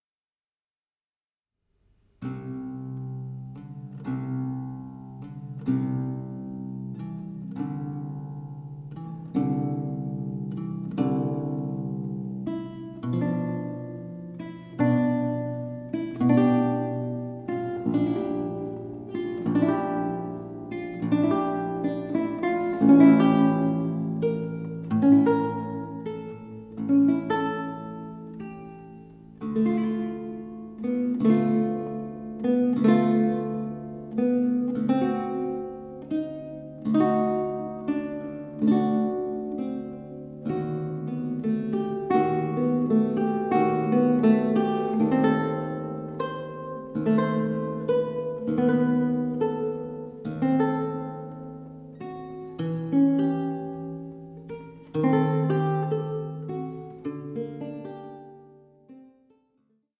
archlute